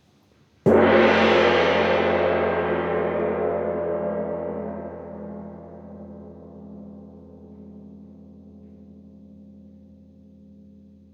petit_1coup_centre.wav